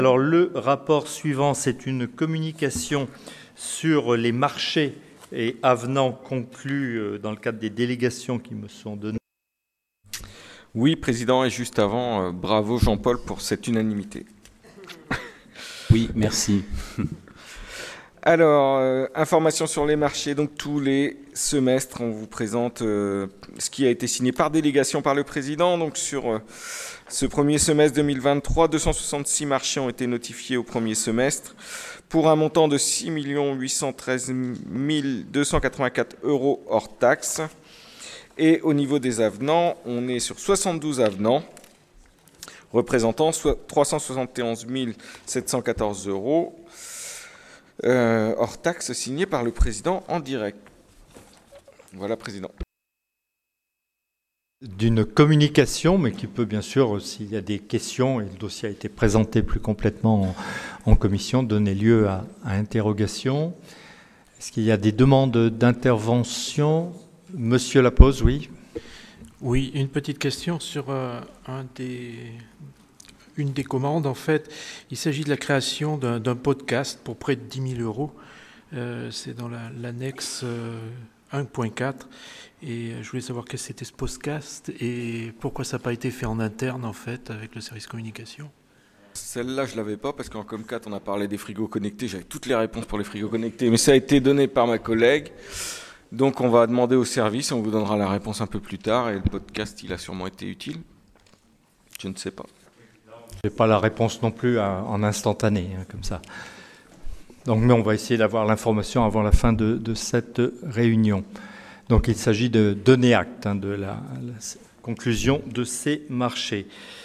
Libellé de la politique Finances, moyens des services, citoyenneté Nature Assemblée départementale